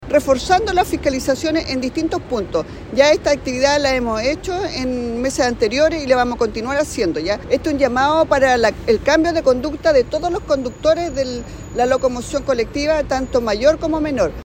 La seremi de Transporte y Telecomunicaciones, Mary Valdebenito, dijo que con estas fiscalizaciones buscan cambiar la conducta de los operadores del transporte público de Temuco y Padre Las Casas.